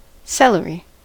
celery: Wikimedia Commons US English Pronunciations
En-us-celery.WAV